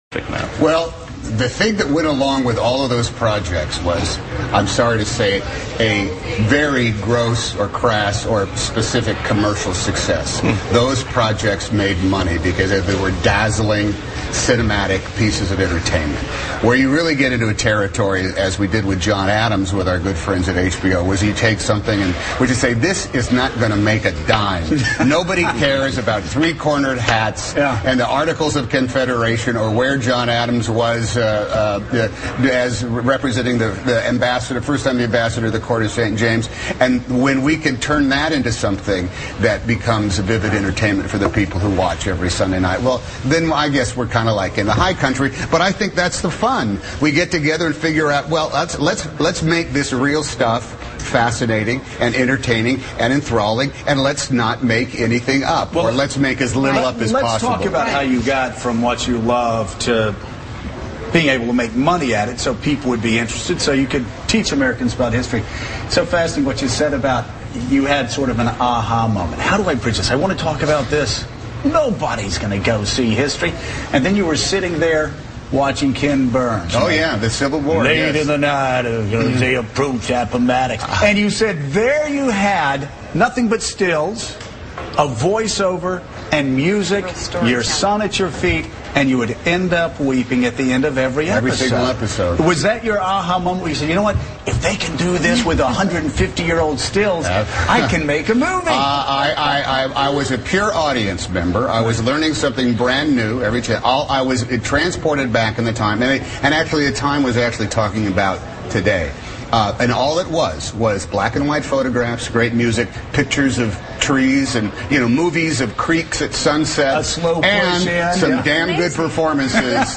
访谈录 2010-03-16&0318 汤姆汉克斯Morning Joe访谈（四 听力文件下载—在线英语听力室